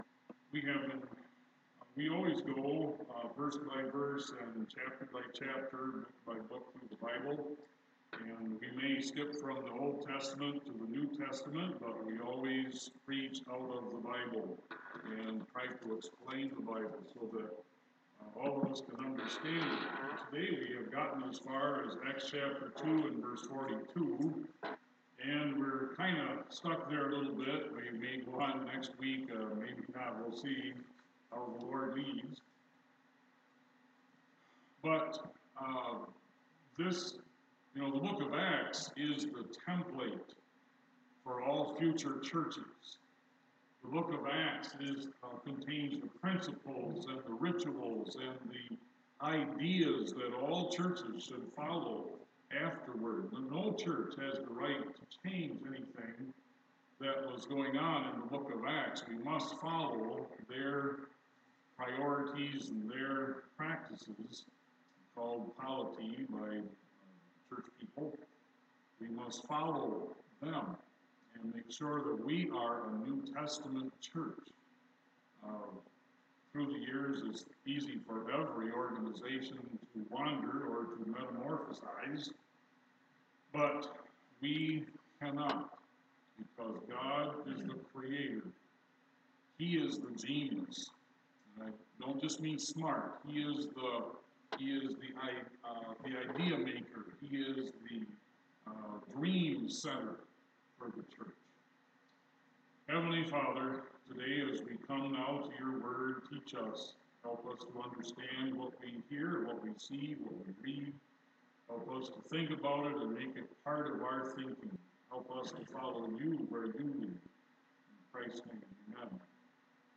Sunday Morning Message: Transmitting our Culture – Acts 2